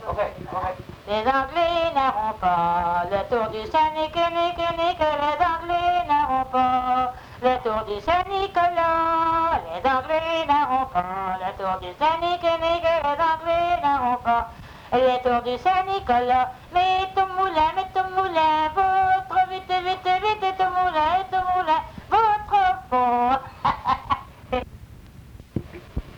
Chanson
Cap St-Georges